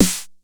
TR 909 Snare 01.wav